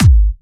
VEC3 Bassdrums Trance 48.wav